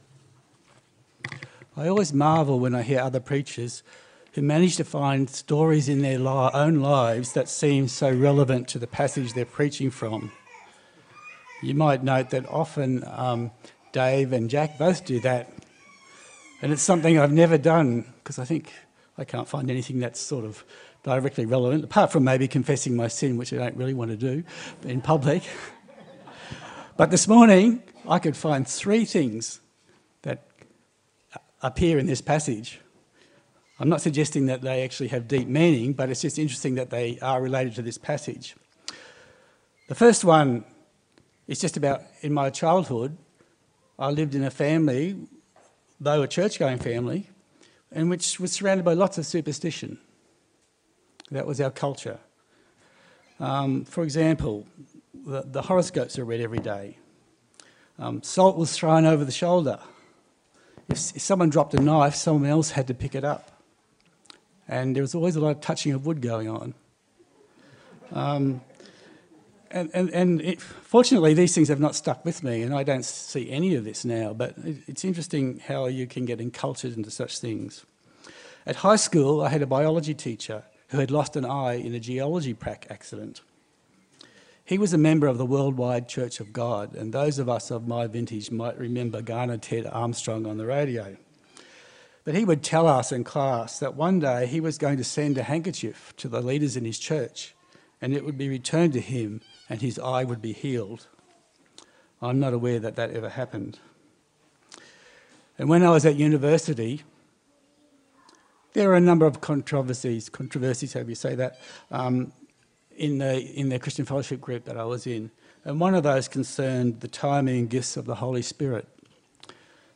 Acts Passage: Acts 19:1-22 Service Type: Sunday Service